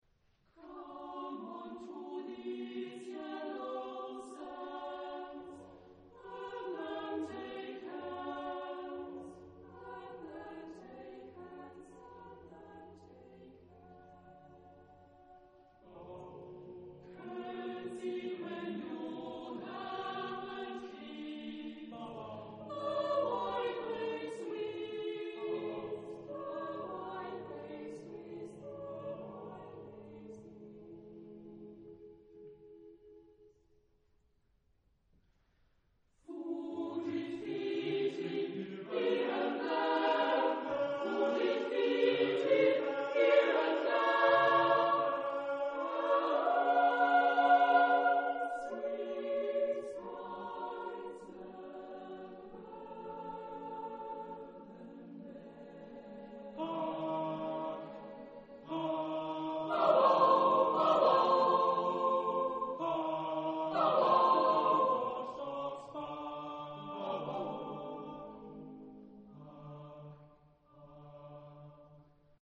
Type of Choir: SSAATTBB  (8 mixed voices )